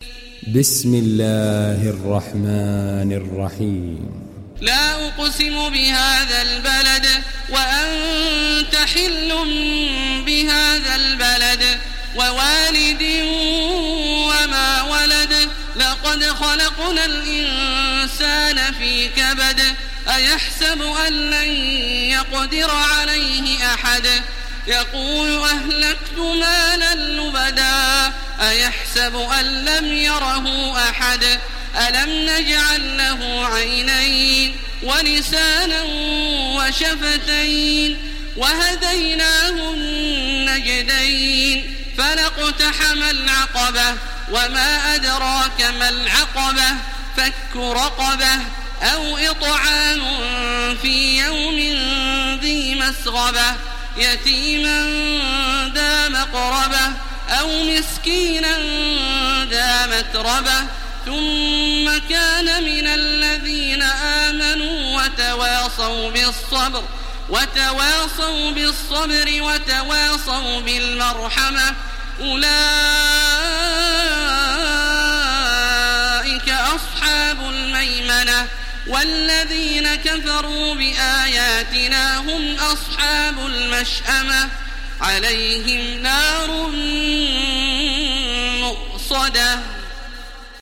Beled Suresi İndir mp3 Taraweeh Makkah 1430 Riwayat Hafs an Asim, Kurani indirin ve mp3 tam doğrudan bağlantılar dinle
İndir Beled Suresi Taraweeh Makkah 1430